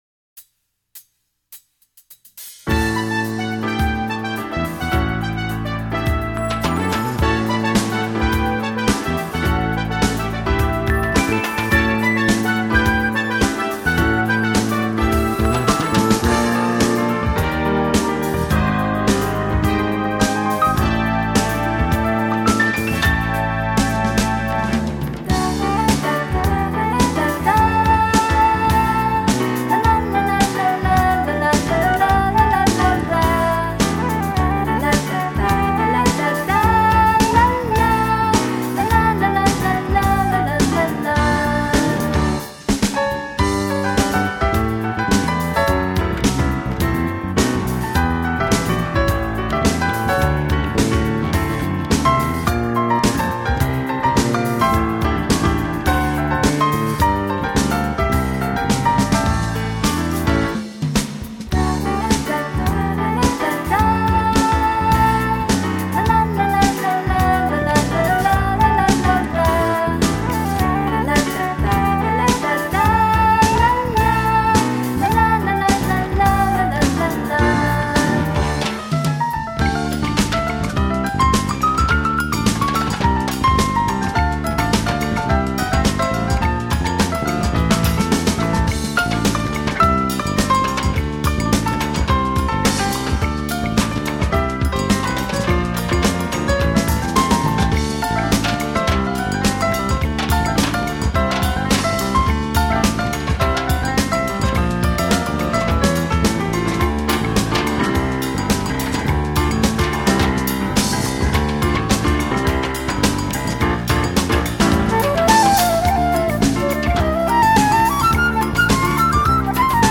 用乐器及音符谱成一首首的感性小品。